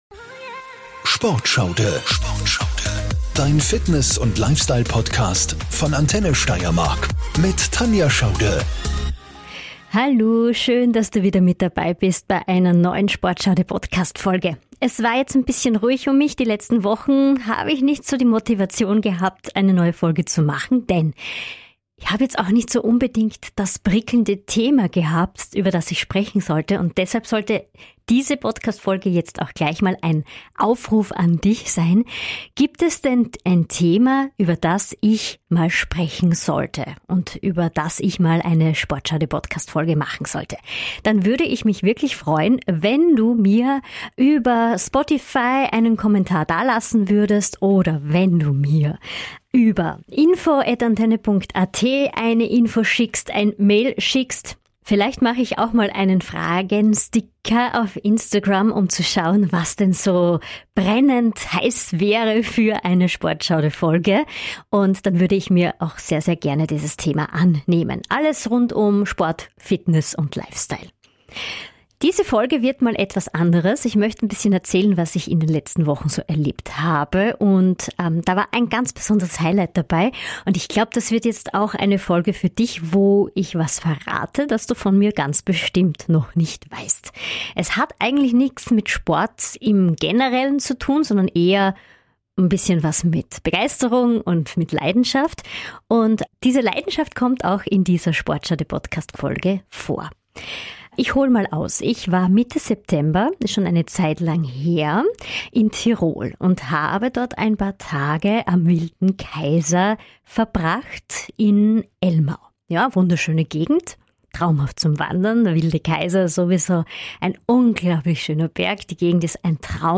SportSchauDe Folge #58 - Interview: Bergdoktor Star Ronja Forcher ~ SportSchauDe Podcast